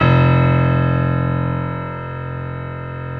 55p-pno04-F0.wav